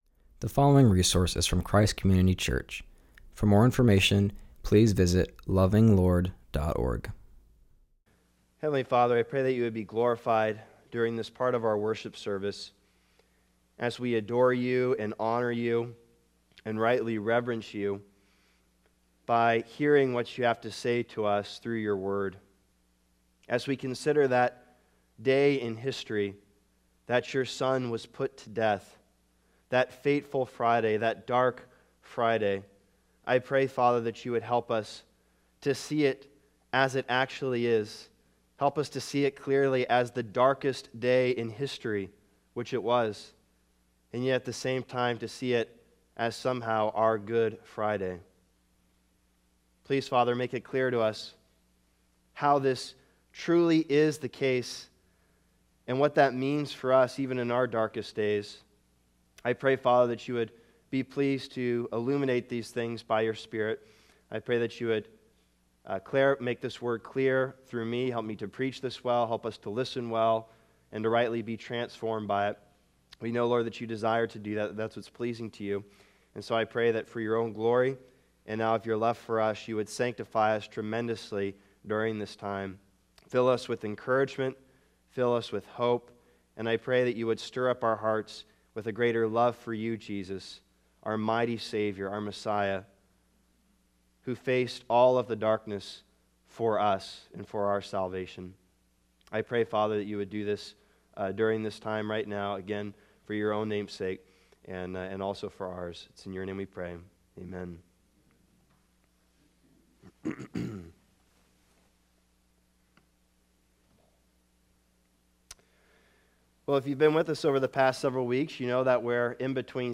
preaches from Luke 22:52-53.